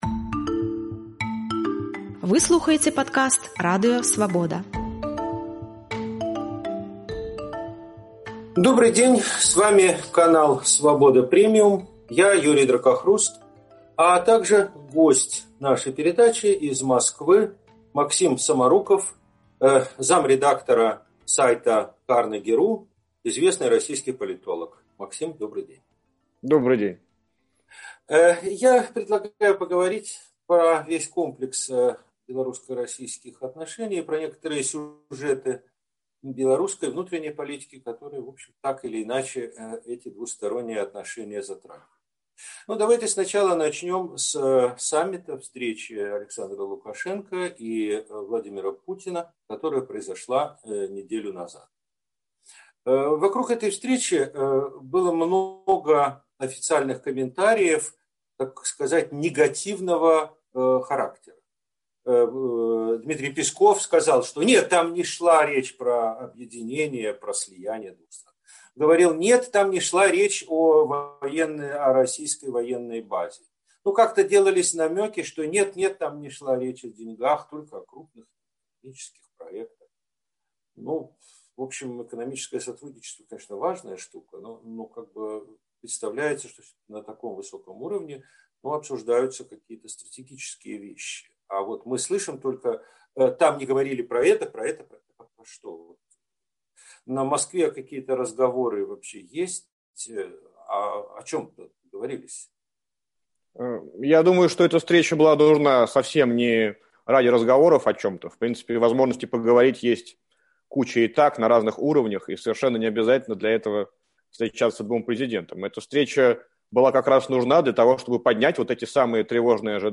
Адказвае расейскі палітоляг